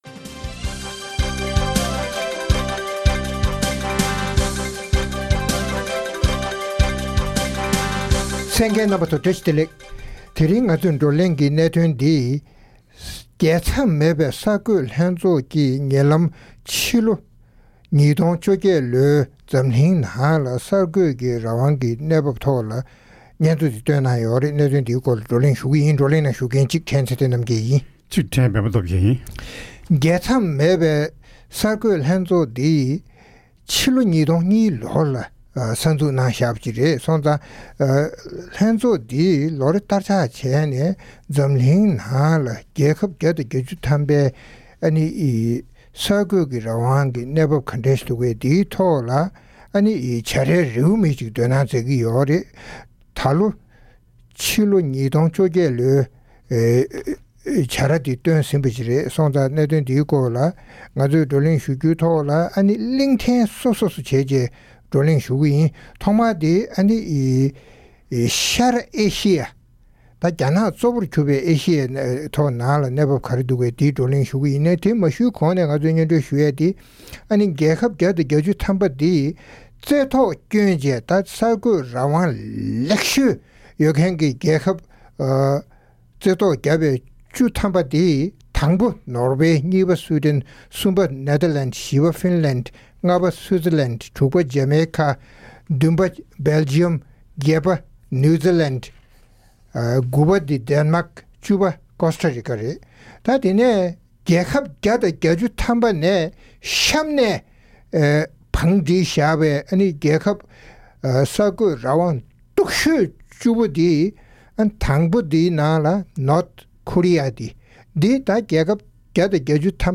༄༅༎ཐེངས་འདིའི་རྩོམ་སྒྲིག་པའི་གླེང་སྟེགས་ཞེས་པའི་ལེ་ཚན་ནང་།ཉེ་ལམ་རྒྱལ་མཚམས་མེད་པའི་གསར་འགོད་ལྷན་ཚོགས་ཀྱིས་རྒྱལ་ཁབ་བརྒྱ་དང་བརྒྱད་ཅུའི་ཕྱི་ལོ་ ༢༠༡༨ ལོའི་གསར་འགོད་རང་དབང་གི་བྱ་རའི་རེའུ་མིག་བསྟན་ཏེ་ཁྱབ་སྤེལ་གནང་བའི་སྐོར་རྩོམ་སྒྲིག་འགན་འཛིན་རྣམ་པས་བགྲོ་གླེང་གནང་བ་ཞིག་གསན་རོགས་གནང་།།